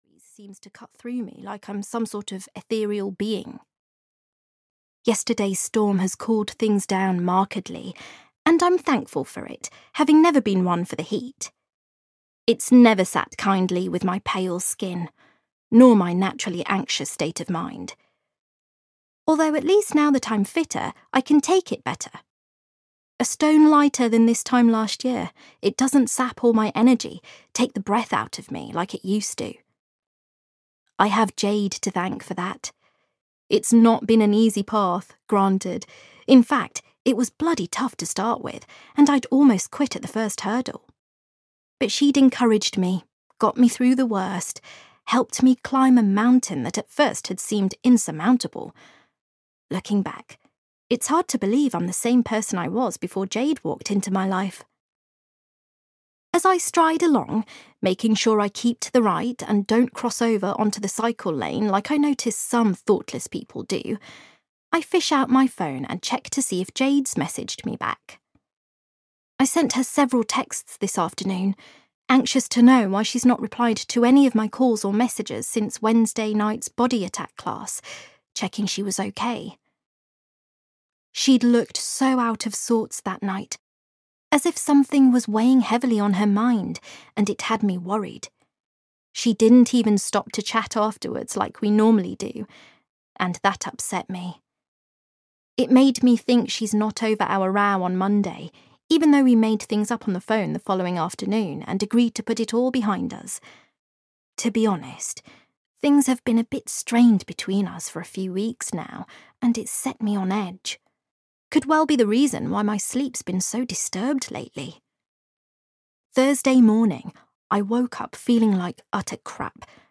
Audio knihaThe Loyal Friend (EN)
Ukázka z knihy